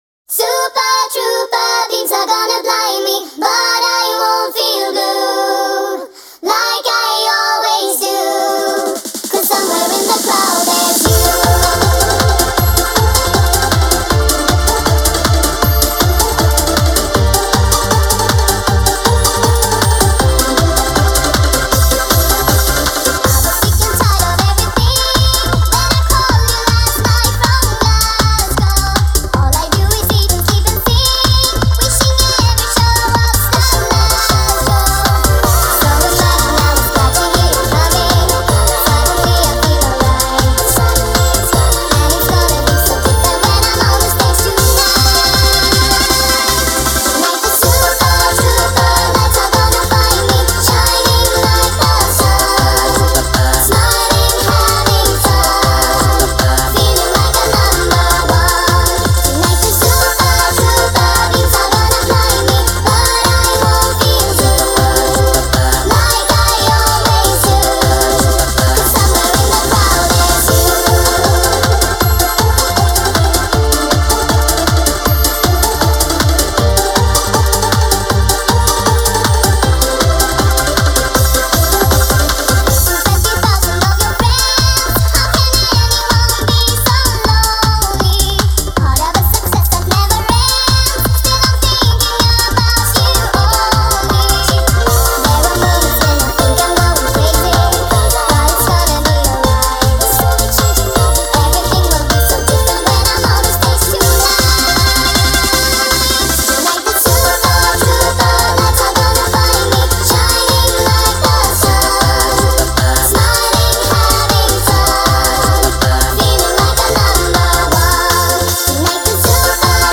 nightcore edit